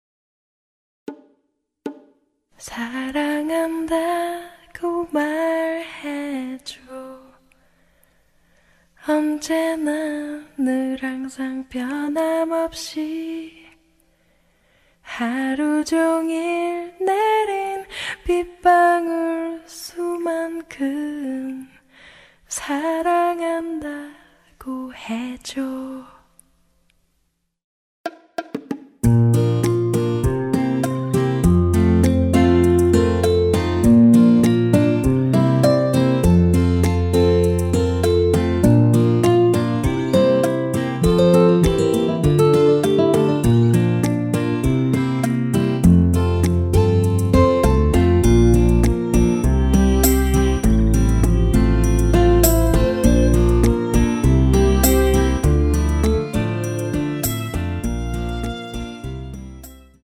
원키에서(-1)내린 시작부분 여성 보컬 추가된 MR입니다.(미리듣기 참조)
앞부분30초, 뒷부분30초씩 편집해서 올려 드리고 있습니다.
중간에 음이 끈어지고 다시 나오는 이유는